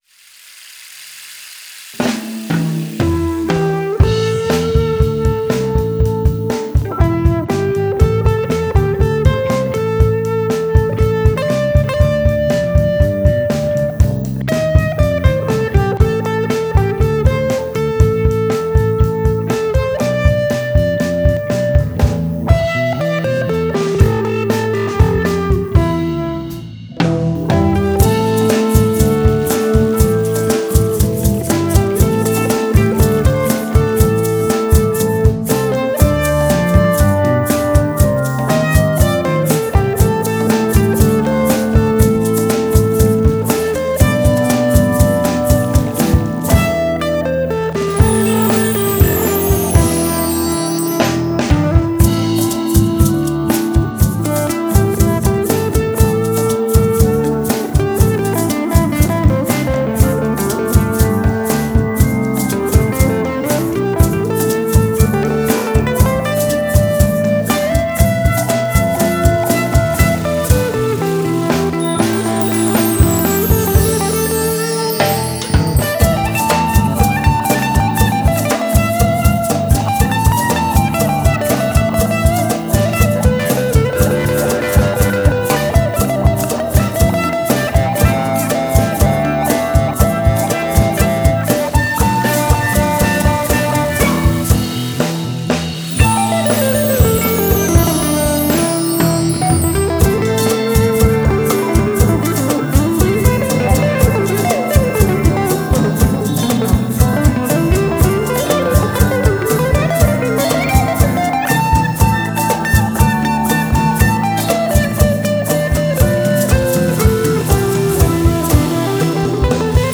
a Jazz Blues Tune